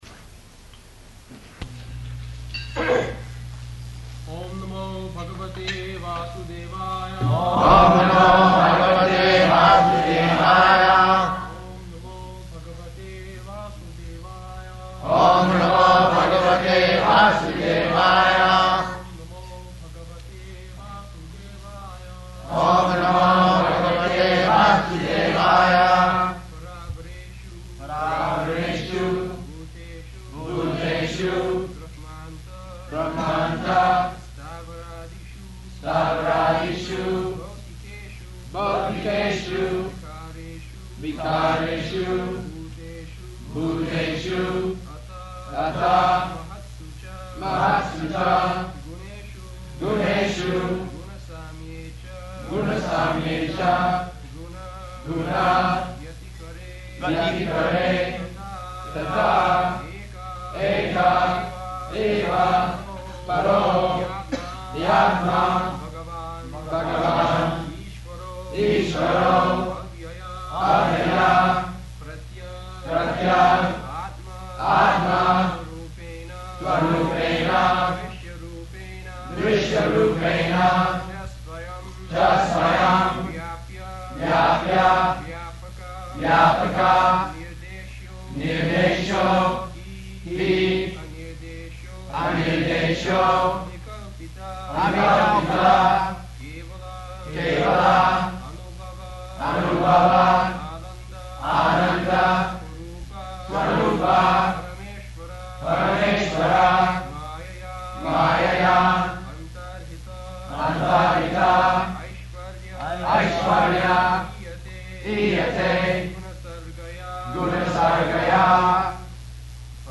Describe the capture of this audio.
-- Type: Srimad-Bhagavatam Dated: July 3rd 1976 Location: Washington D.C. Audio file